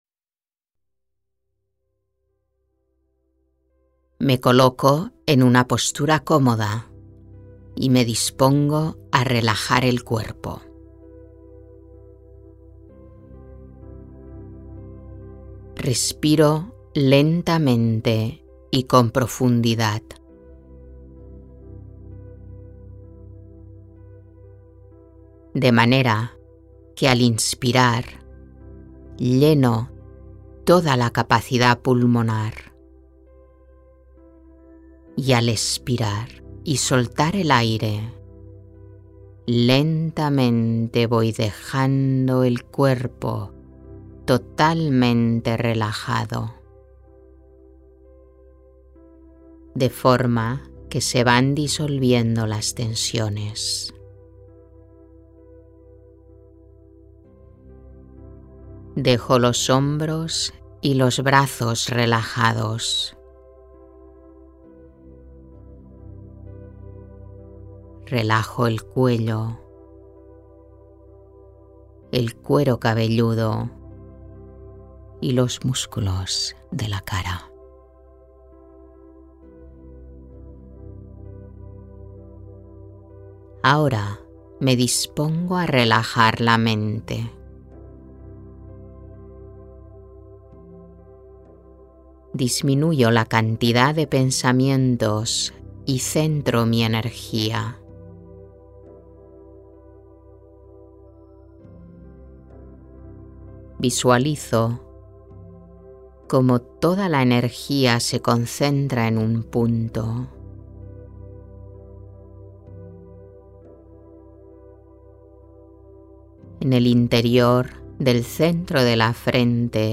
meditaciones-guiadas